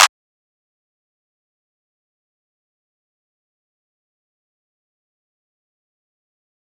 (CLAP) MotorSport.wav